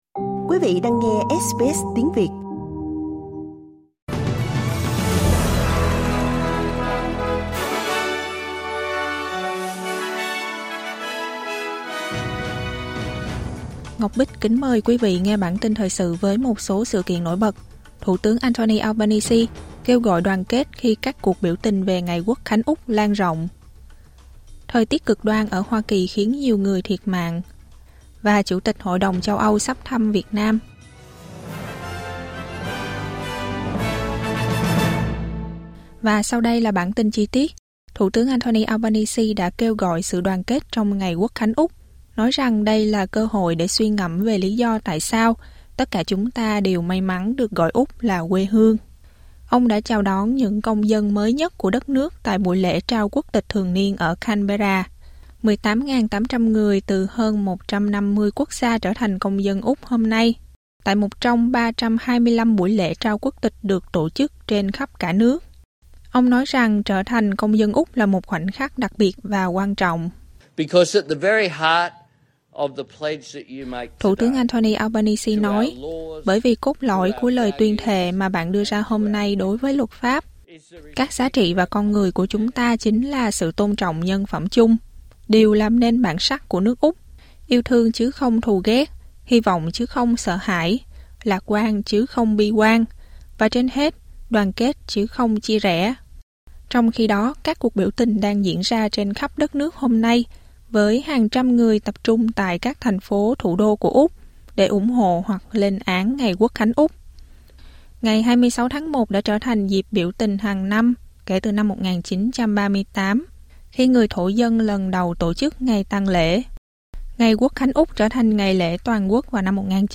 Một số tin tức trong bản tin thời sự của SBS Tiếng Việt.